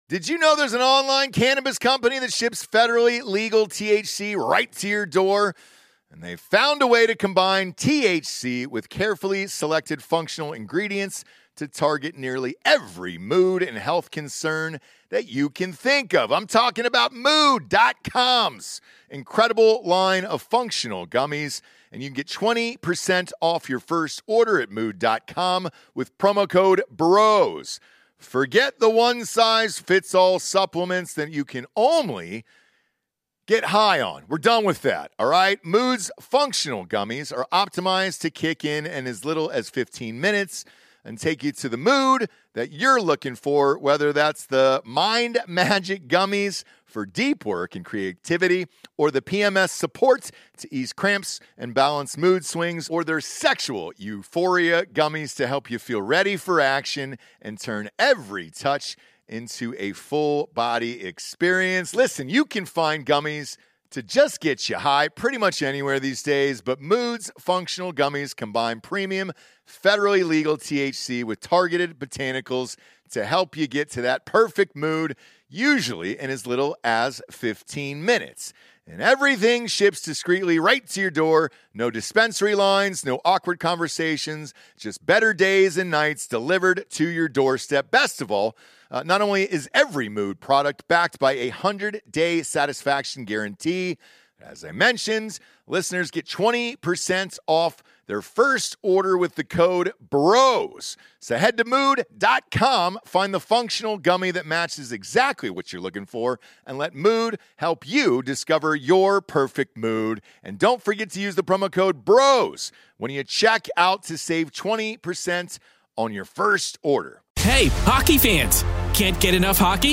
Comedians Doug Stanhope, Paul Provenza